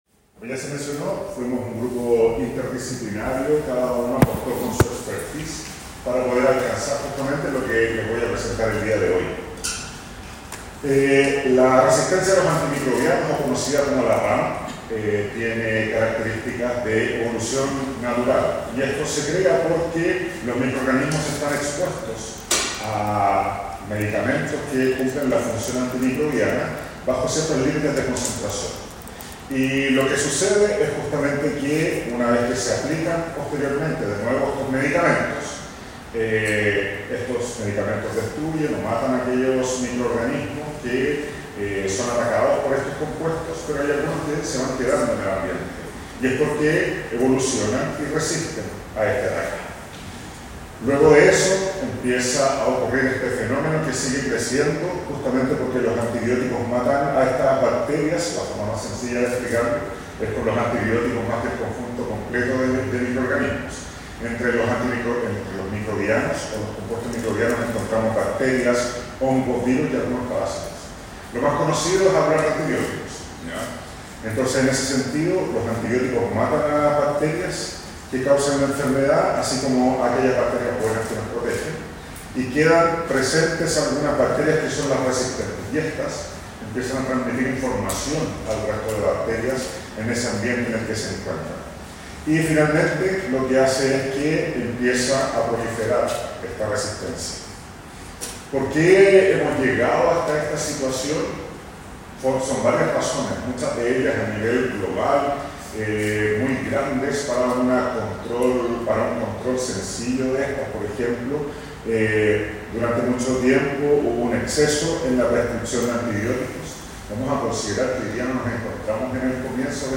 Audio seminario